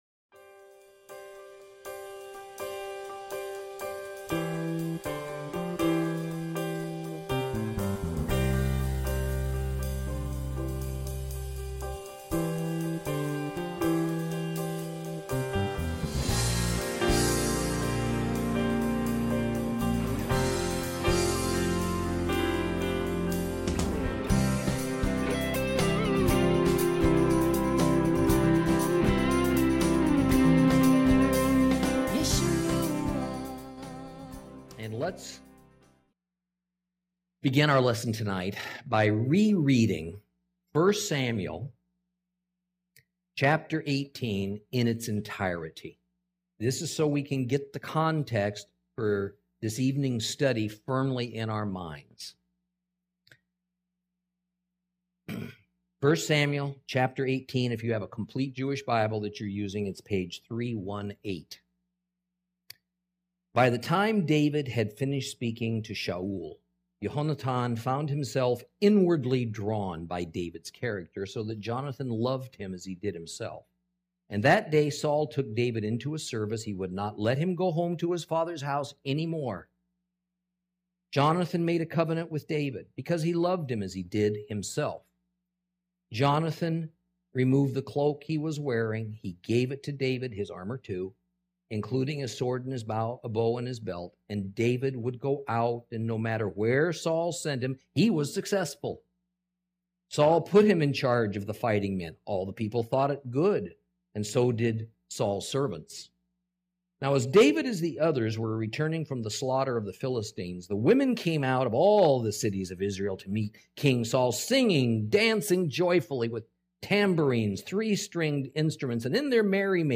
Lesson 31 Ch18 Ch19 - Torah Class